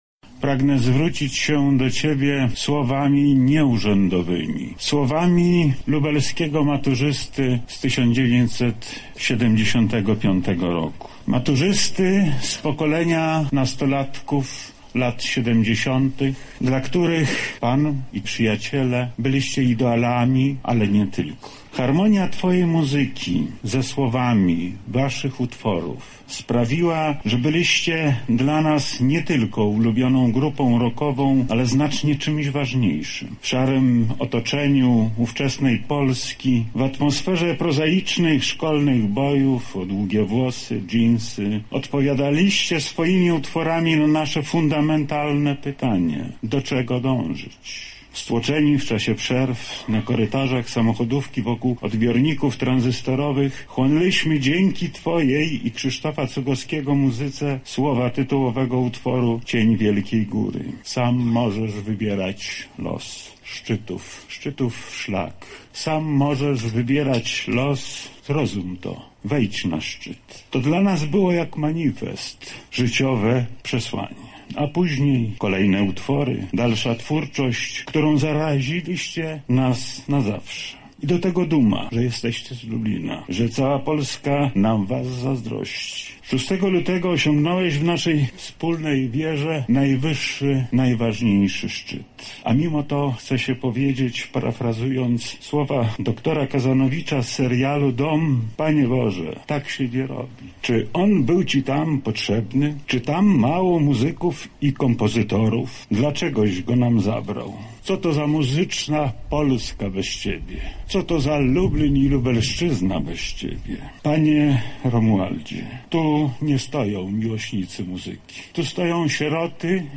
Przed złożeniem trumny z ciałem muzyka do grobu swoje wspomnienia o artyście przypomniał także wojewoda lubelski Lech Sprawka: